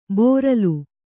♪ bōralu